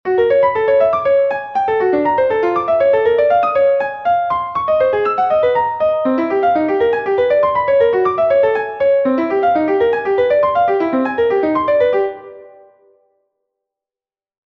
A few measures of whole-tone arpeggiations, based on a random chord progression of extended chords.
And here is the audio of the above transcription of the zany whole-tone wackiness: